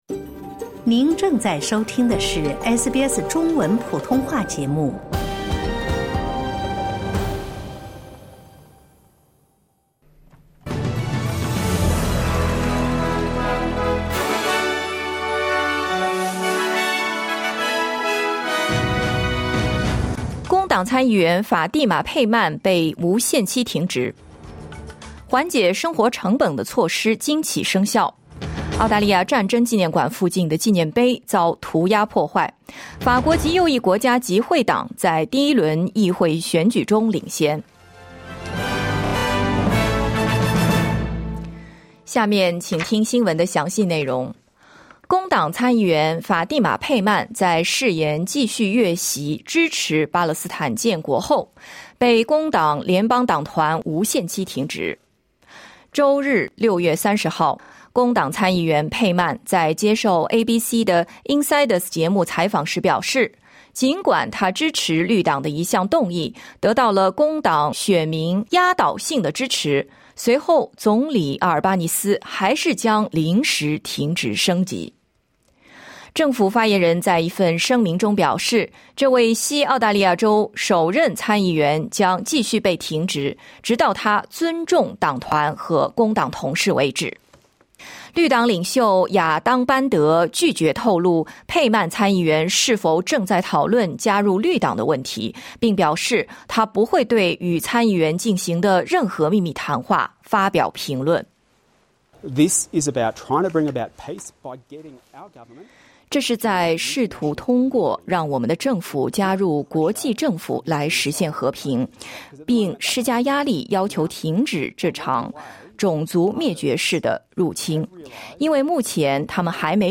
SBS早新闻（2024年7月1日）